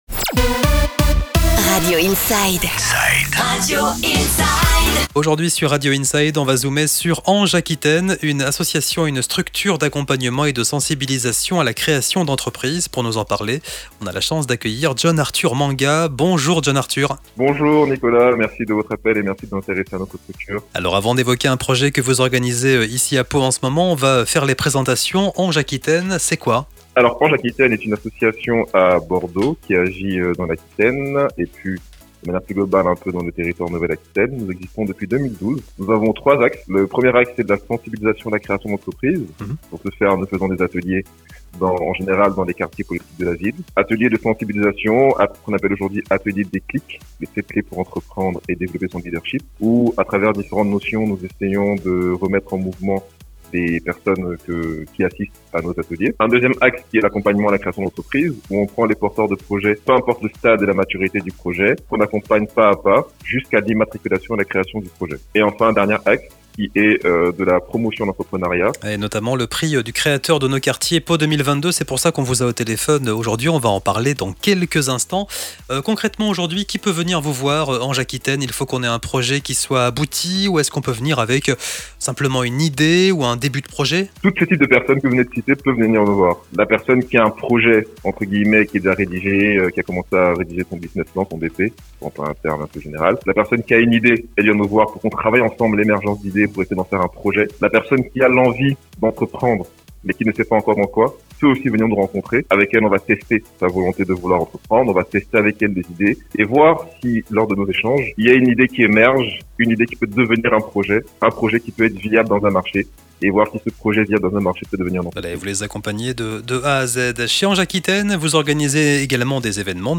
INTERVIEW ANJE AQUITAINE - PRIX CREATEUR ENTREPRISE